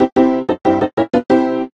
8bit_piano_lead_vo_01.ogg